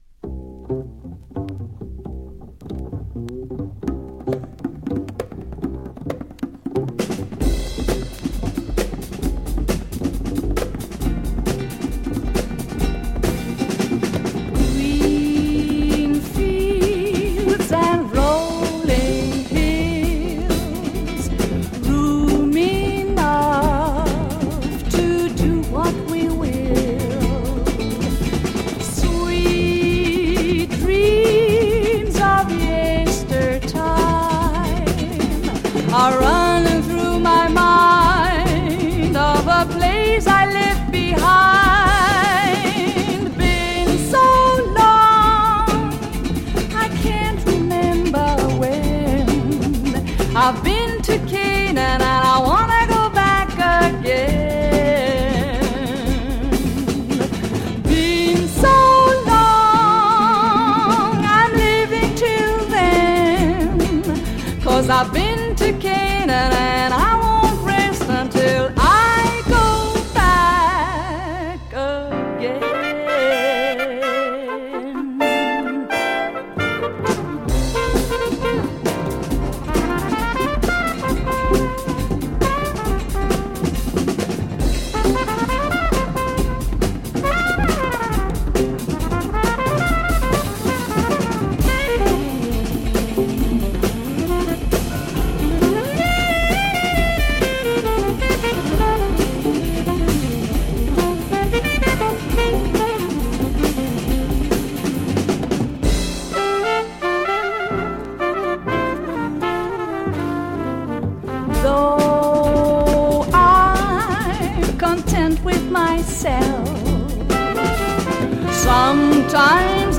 Jazz Groove sweden